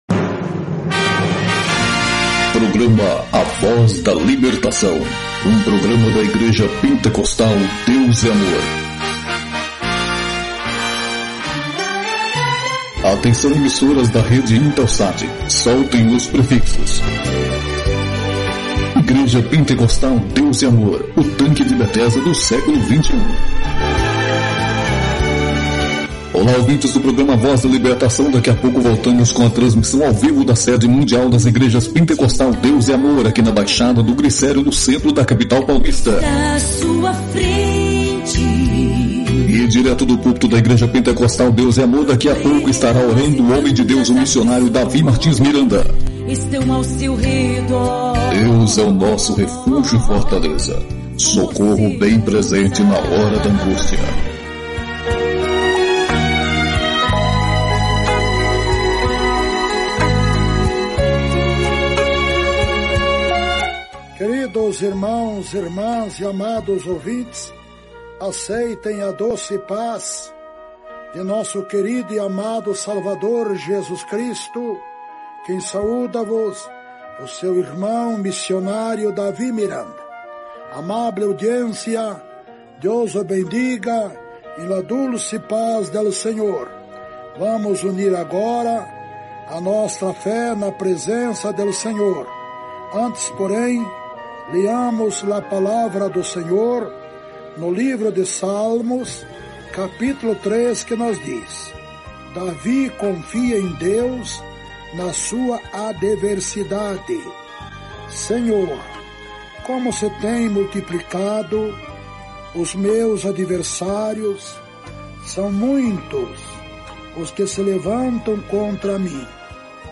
LOCUÇÃO NO ESTILO A VOZ sound effects free download
LOCUÇÃO NO ESTILO A VOZ DA LIBERTAÇÃO, DA IGREJA DEUS É AMOR (IPDA)